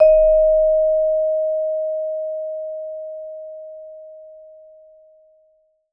Index of /90_sSampleCDs/Sampleheads - Dave Samuels Marimba & Vibes/VIBE CMB 2C